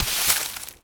Broom Sweeping
sweeping_broom_leaves_stones_12.wav